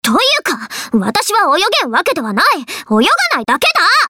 わたし いもうと ノーバディ Es 篝 橙八 鵜丸 総一朗 ゼクス ブレインキャット 姫鶴 ひなた 天ノ矛坂 冥 久音＝グラムレッド＝シュトルハイム リッパー キリ CV：水瀬 いのり 由緒正しい天ノ矛坂家の一人娘。